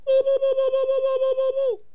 indiattack2.wav